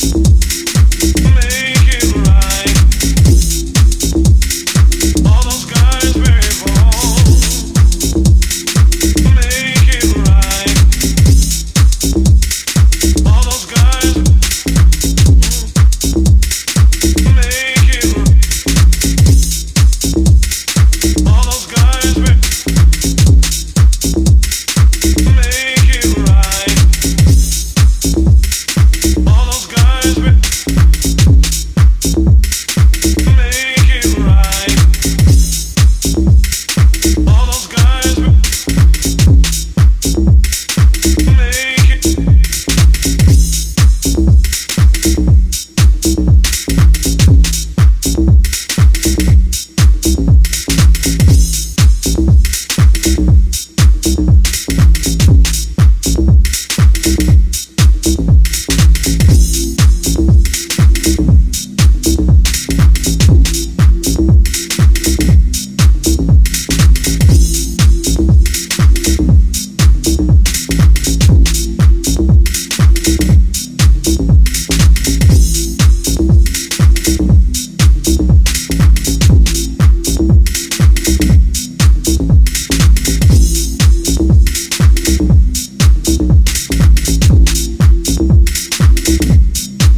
このA面を筆頭に、オーセンティックでありながらもヒプノティックな志向に向かう、90年代中盤地下の空気感を秘めた全3曲。